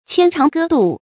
牽腸割肚 注音： ㄑㄧㄢ ㄔㄤˊ ㄍㄜ ㄉㄨˋ 讀音讀法： 意思解釋： ①形容非常想念。